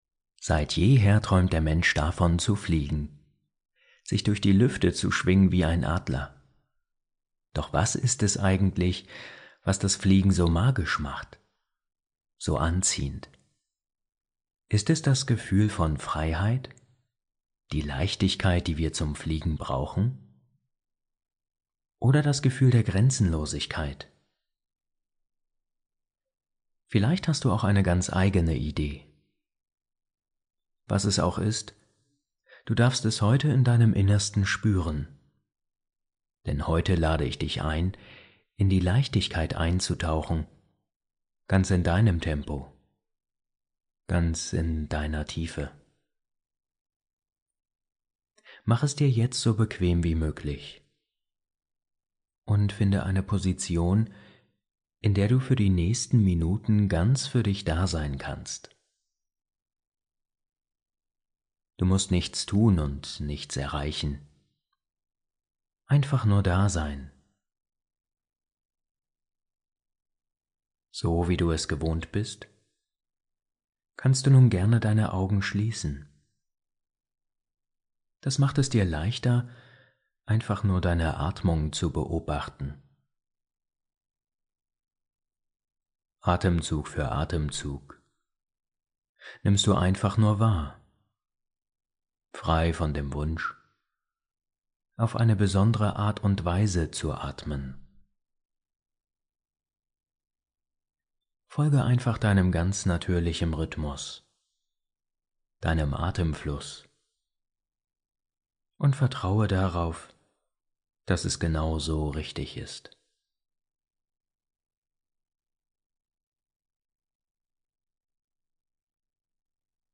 Traumreise: Die Feder im Wind ~ Entspannungshelden – Meditationen zum Einschlafen, Traumreisen & Entspannung Podcast